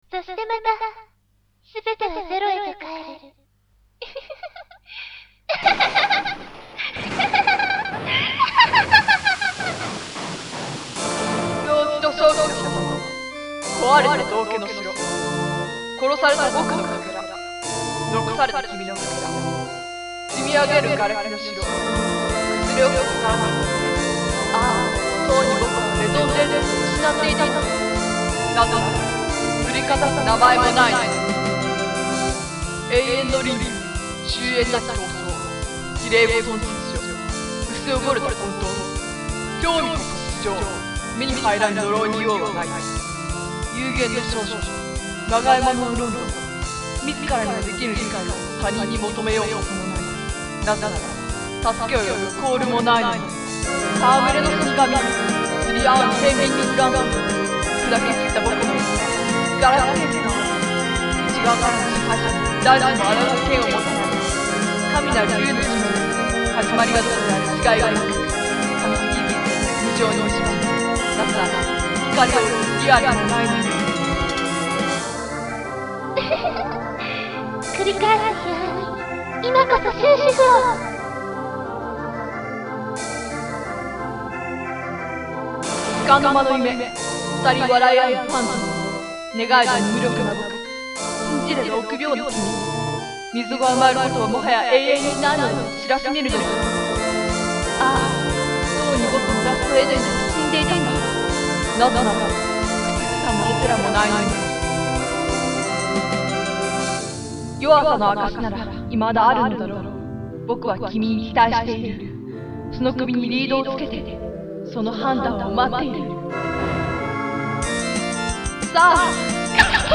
声入りver./歌詞（ＰＣ専用）
不気味でちょこっと切ない朗読曲という謎の物体ですみません；；
壊れた声が幾重にも木霊していく。
tsumikikuzusi_voice.mp3